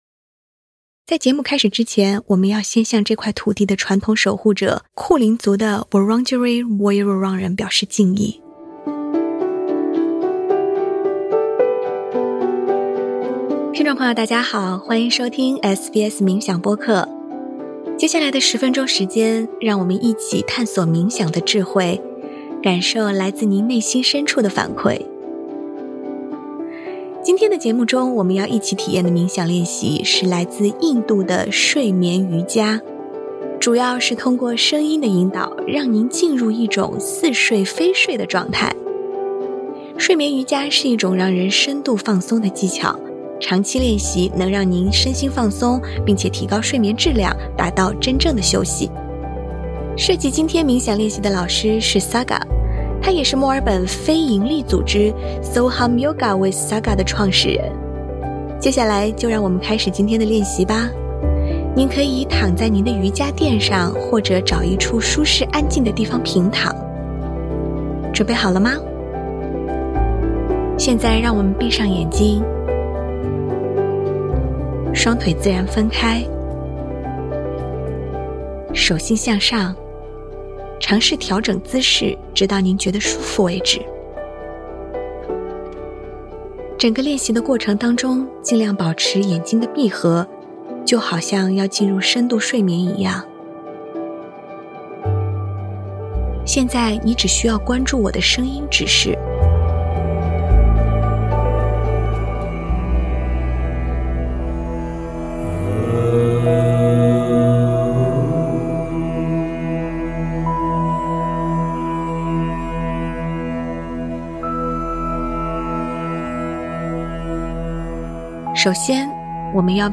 睡眠瑜伽：让您深度放松的冥想练习